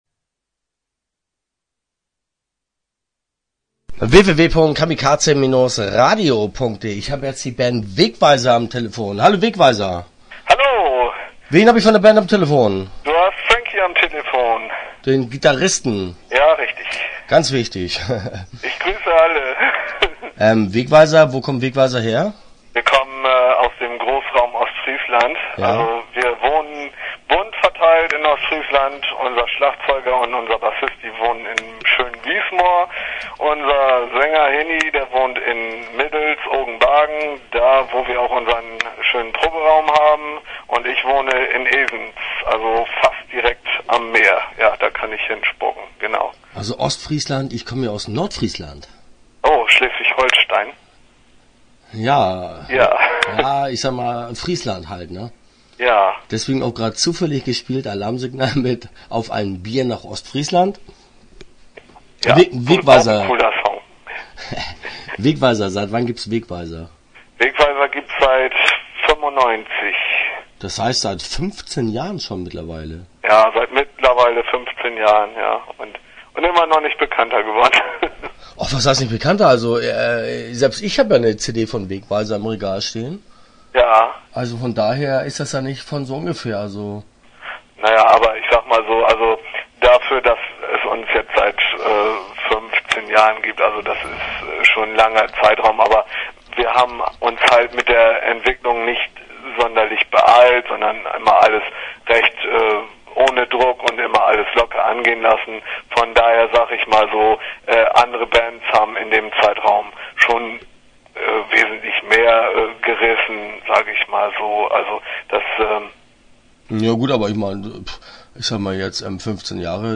Start » Interviews » Wegweiser